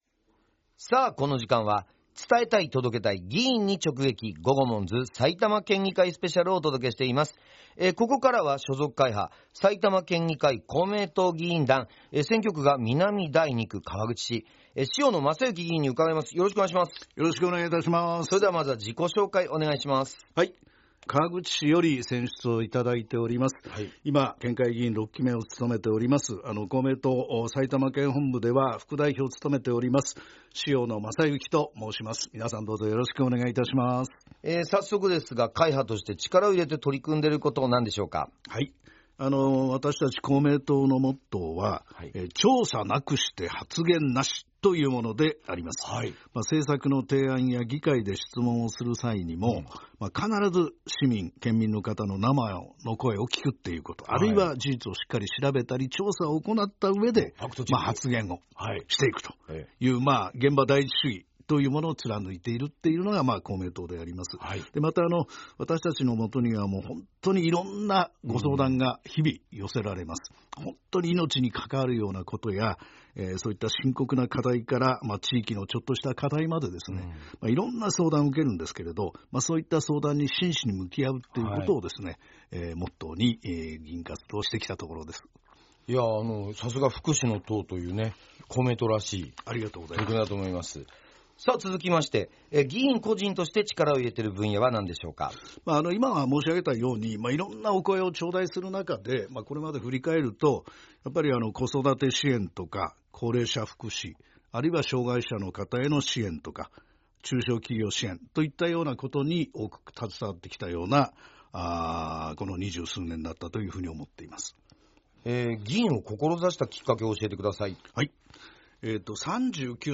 県議会議長や主要会派の議員が「GOGOMONZ」パーソナリティーで落語家の三遊亭鬼丸さんと、所属会派の紹介、力を入れている分野、議員を志したきっかけ、地元の好きなところなどについて軽快なトークを展開しました。
11月10日（月曜日）と11月11日（火曜日）にFM NACK5のスタジオにてラジオ収録が行われました。